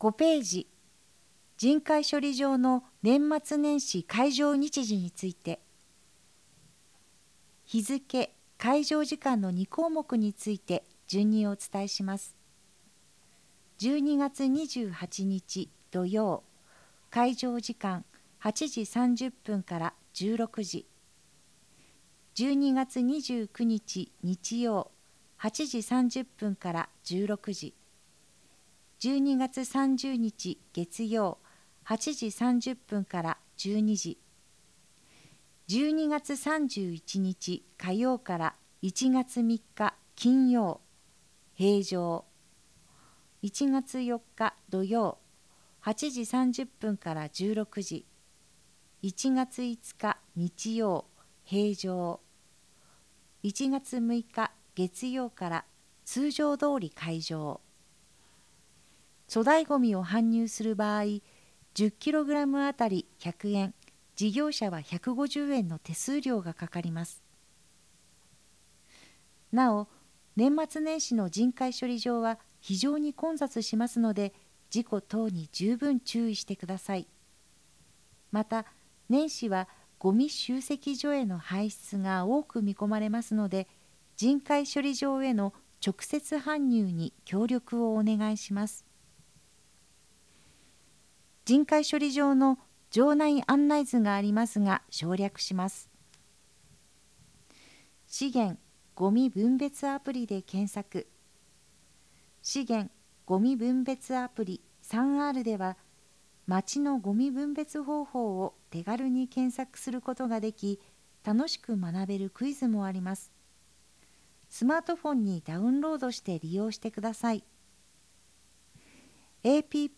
音声データ　軽井沢図書館朗読ボランティア「オオルリ」による朗読です